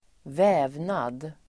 Uttal: [²v'ä:vnad]